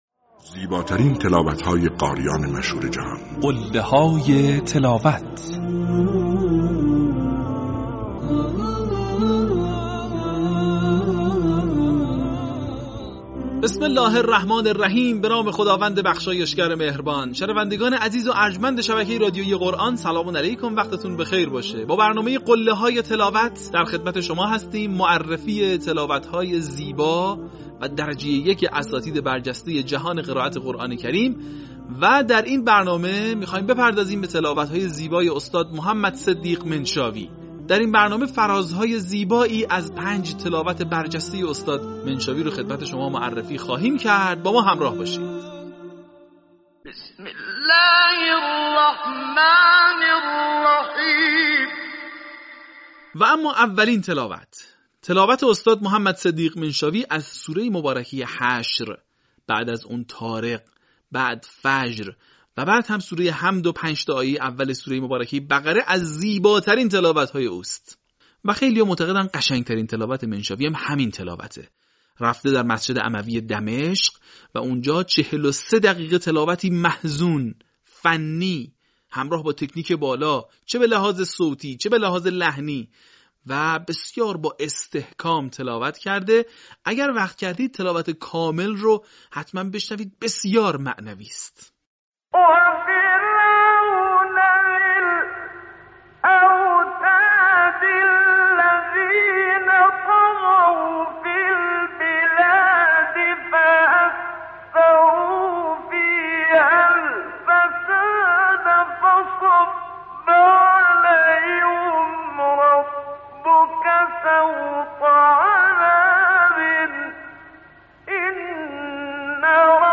این مجموعه شامل تلاوت‌های ماندگار قاریان بین‌المللی مصری است که تاکنون 40 قسمت آن از ایکنا منتشر شده است.
قله‌های تلاوت محمد صدیق منشاوی